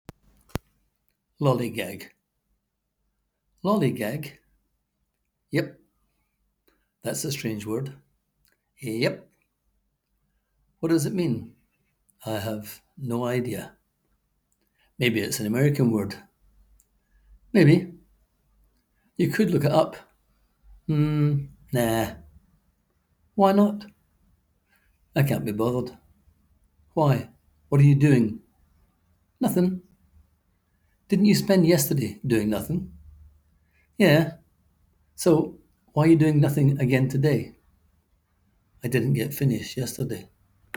Click here to hear the author read his words: